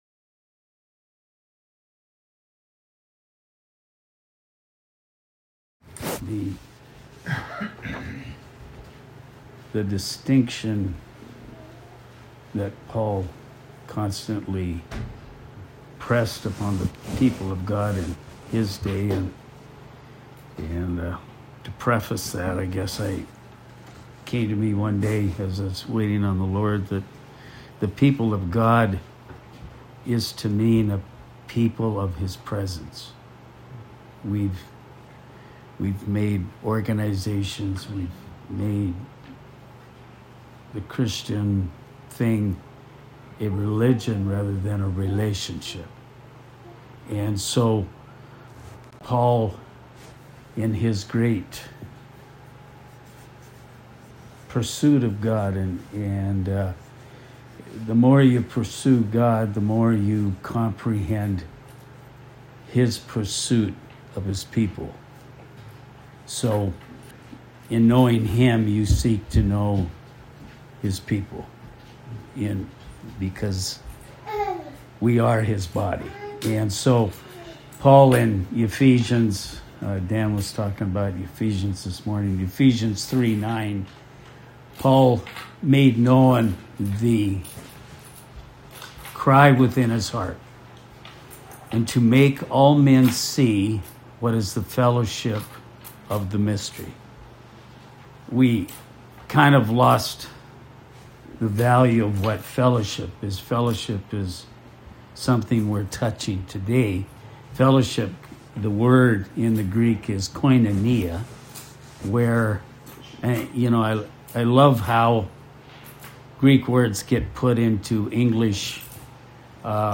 But the manifestation of the Spirit is given to every man to profit withal . 1 Corinthians 12:4-7 Related Post: Faith, Fire, and Fellowship ( LINK ) Shared in a small home group.